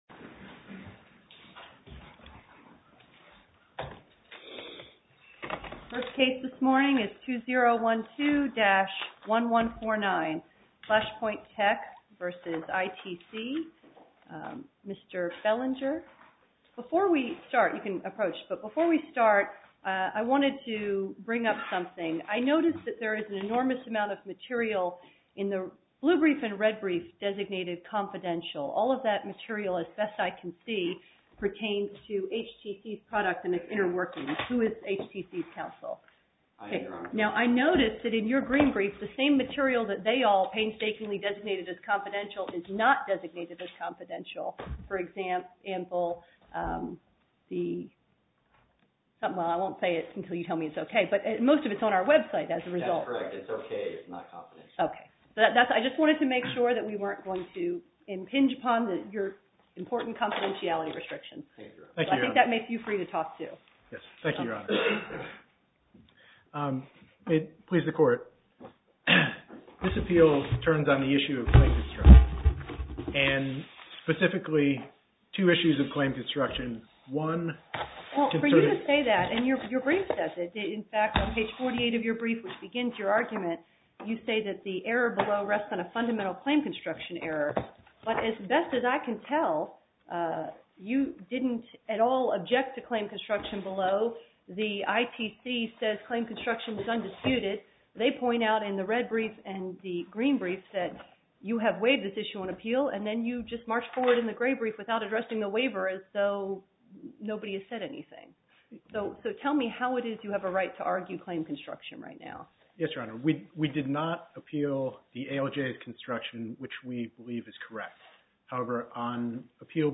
To listen to more oral argument recordings, follow this link: Listen To Oral Arguments.